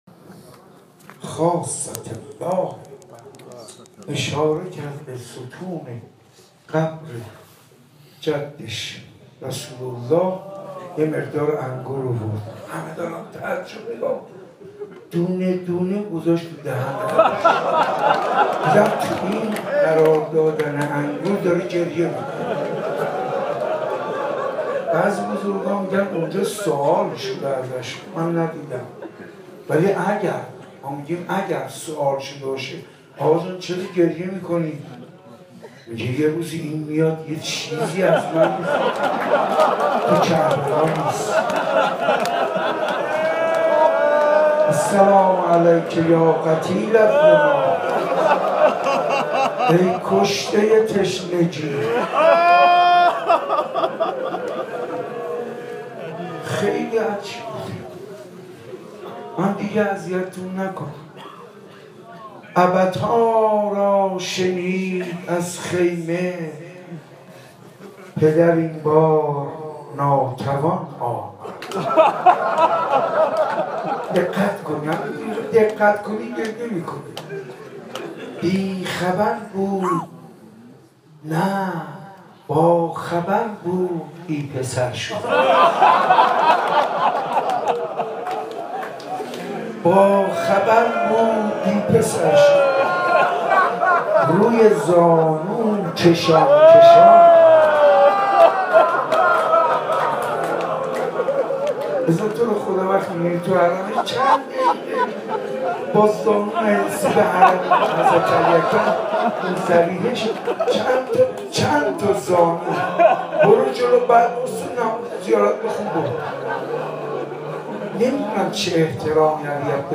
در صبح هشتم محرم